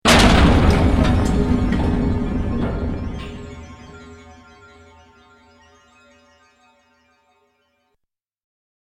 ギャリーン（141KB）
マルチメディアカード記録済み効果音12種類